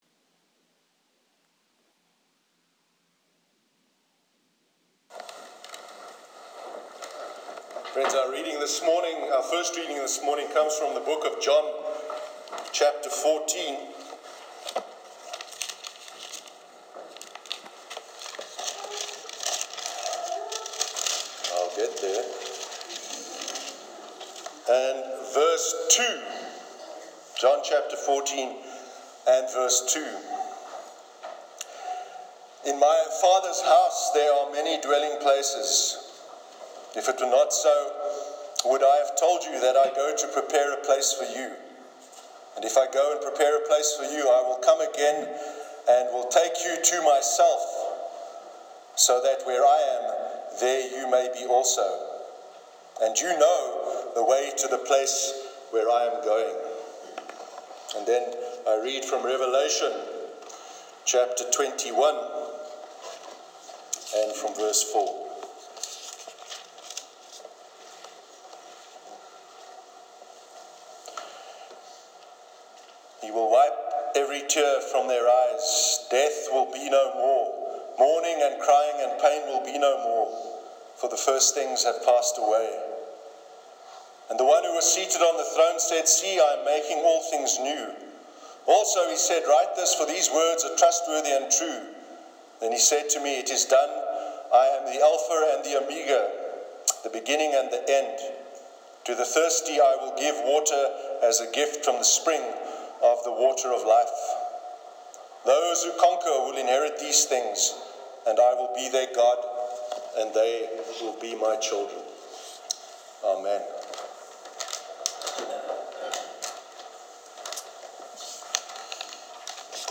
Sermon on the nature of heaven